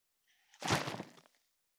340ペットボトル,ワインボトルを振る,水の音,ジュースを振る,シャカシャカ,
ペットボトル